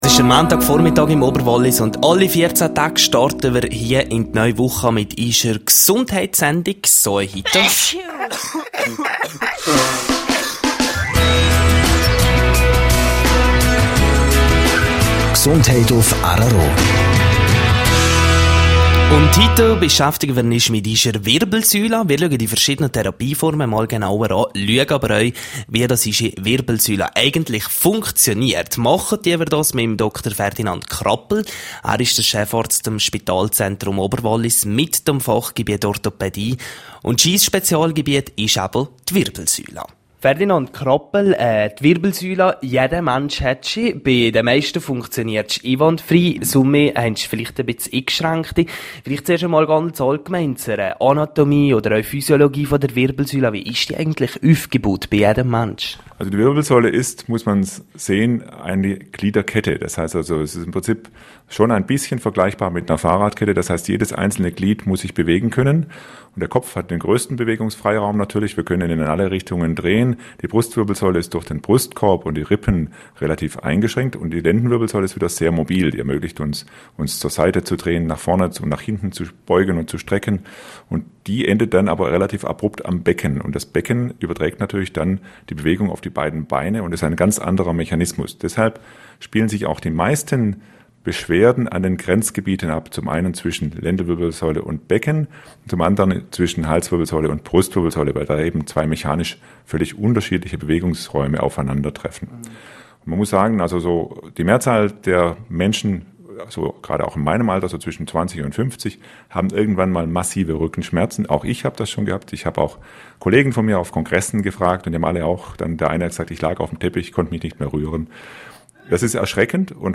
Am Spitalzentrum Oberwallis können viele Patienten mittels einer konservativen Therapie behandelt werden./bj Interview zum Thema (Quelle: rro)